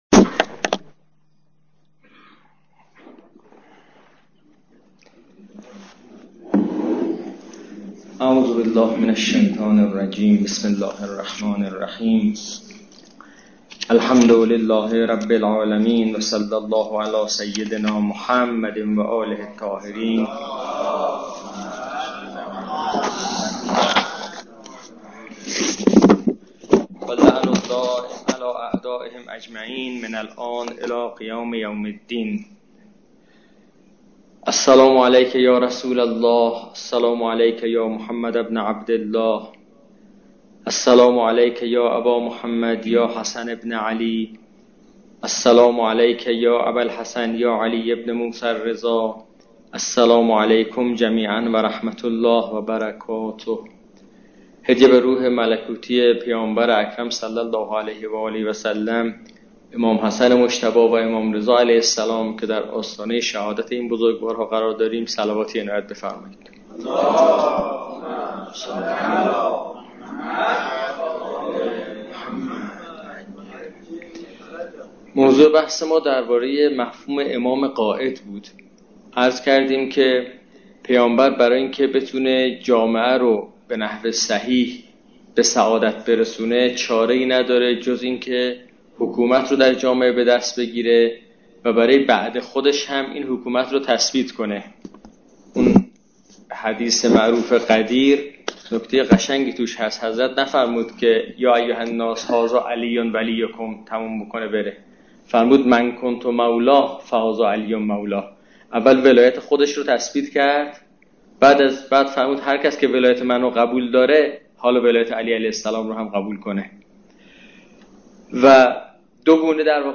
این بحث را به صورت خیلی فشرده‌تر، دو روز قبلش در دو جلسه در مسجد دانشگاه باقرالعلوم ع در قم ارائه کردم با عنوان «امامت امام قاعد»